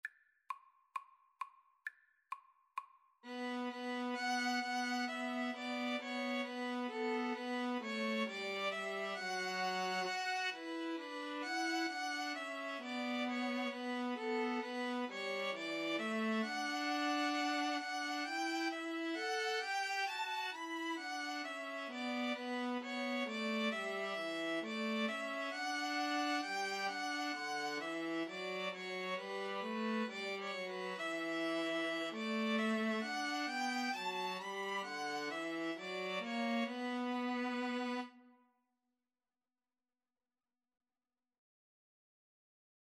Violin 1Violin 2Viola
The melody is in the minor mode.
4/4 (View more 4/4 Music)